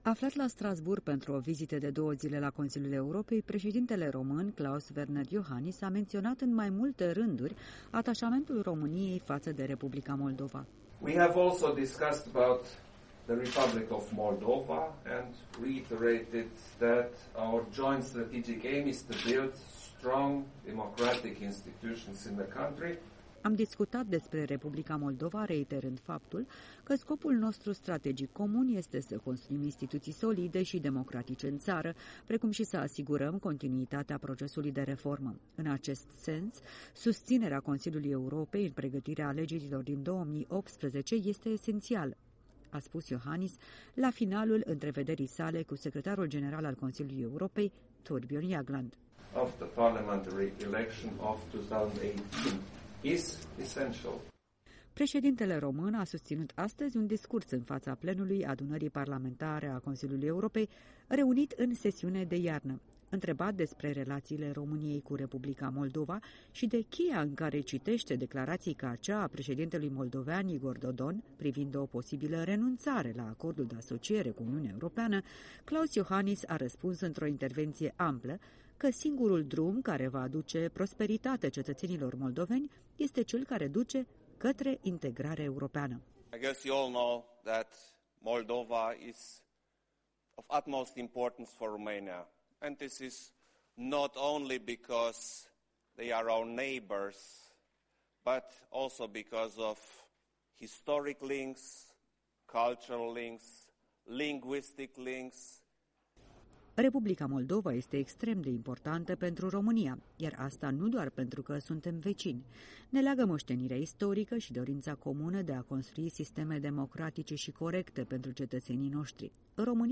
În direct de la Strasbourg